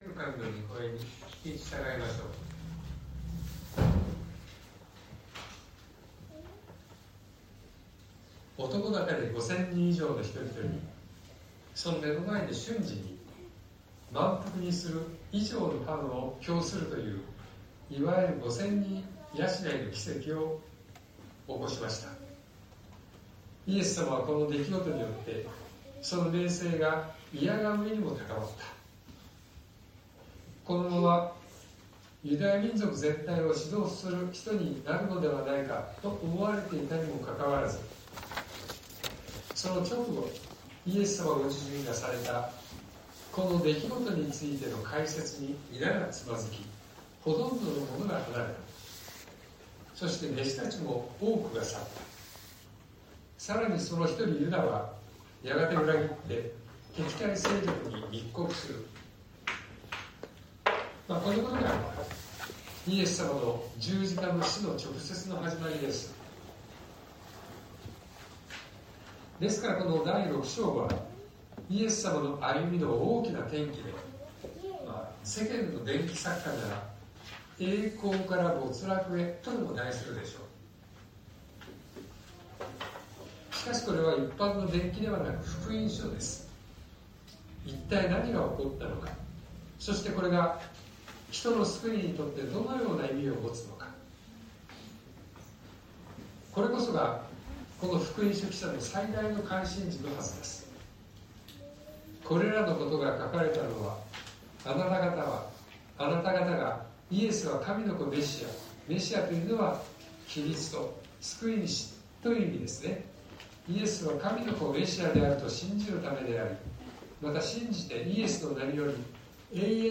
2026年04月19日朝の礼拝「離反と裏切り」東京教会